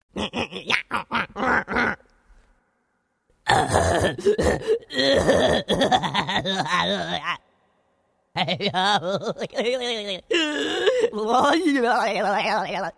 Другие рингтоны по запросу: | Теги: Идиот, смех, Laugh
Категория: Различные звуковые реалтоны